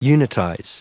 Prononciation du mot unitize en anglais (fichier audio)
Prononciation du mot : unitize